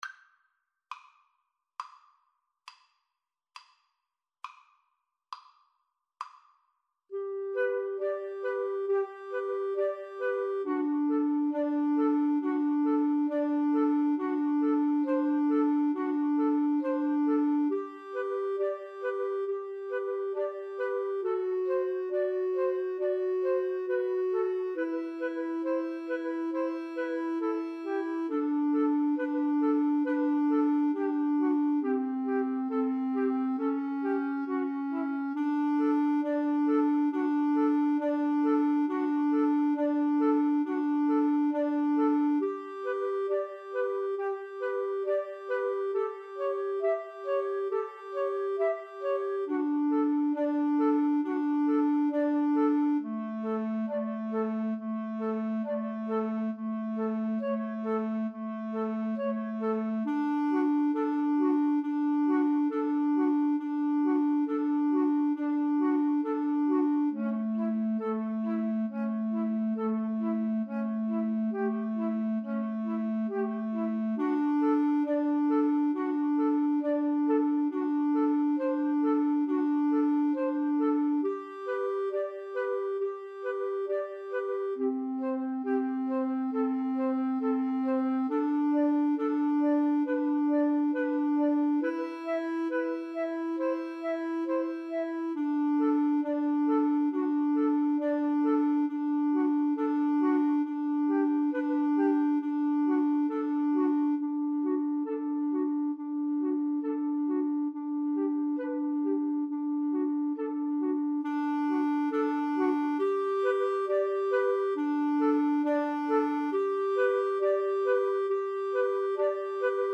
= 34 Grave
Classical (View more Classical 2-Flutes-Clarinet Music)